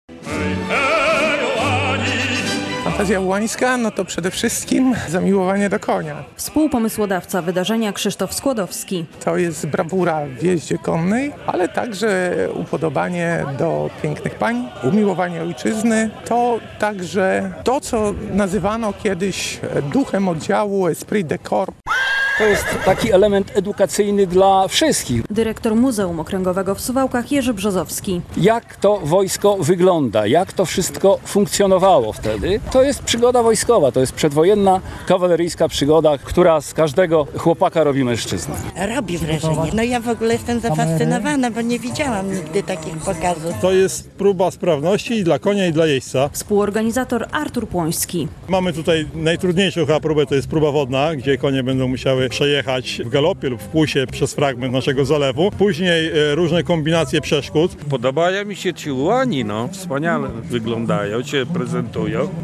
Pokaz musztry, próba dzielności konia i prezentacja sprzętu wojskowego - w Suwałkach po raz 24. rozpoczął się dwudniowy Piknik Kawaleryjski.